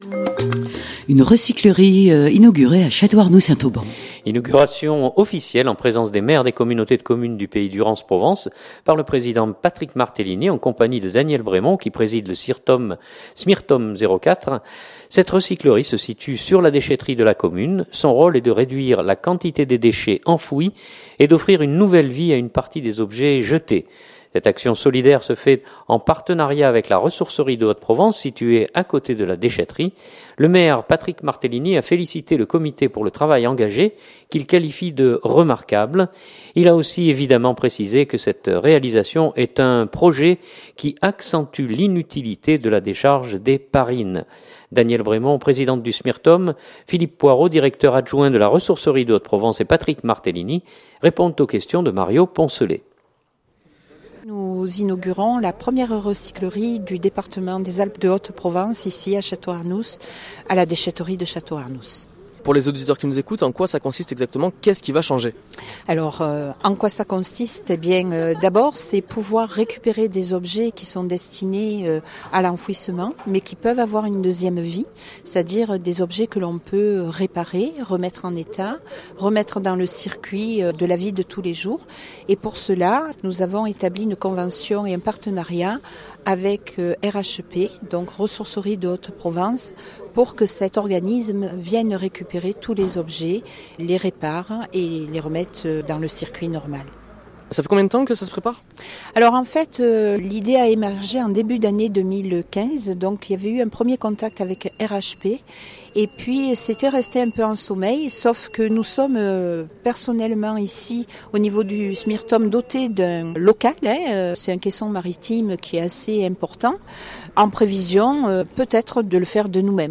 Inauguration officielle en présence des maires des communautés de communes du Pays Durance Provence par le président Patrick Martellini en compagnie de Danièle Brémond, qui préside le SMIRTOM 04.